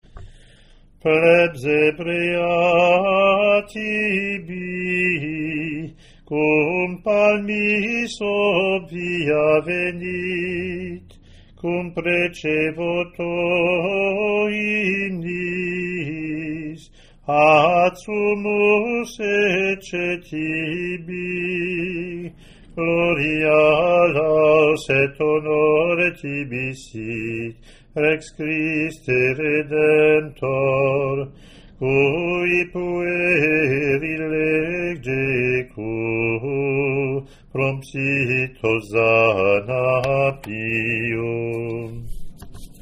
hwps-hymngloriav3-gm.mp3